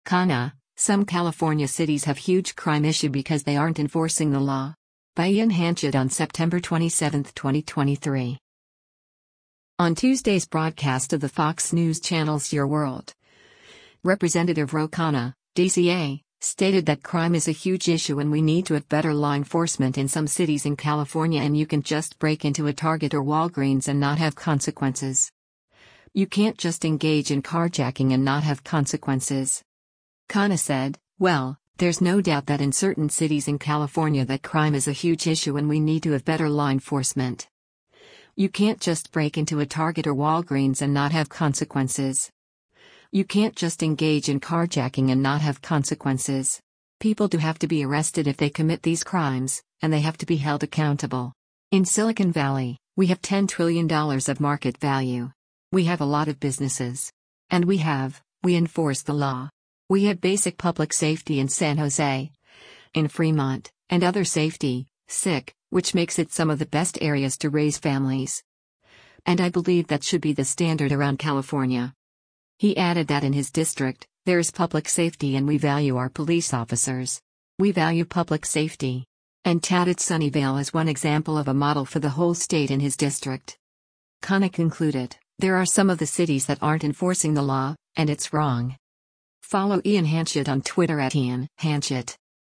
On Tuesday’s broadcast of the Fox News Channel’s “Your World,” Rep. Ro Khanna (D-CA) stated that “crime is a huge issue and we need to have better law enforcement” in some cities in California and “You can’t just break into a Target or Walgreens and not have consequences. You can’t just engage in carjacking and not have consequences.”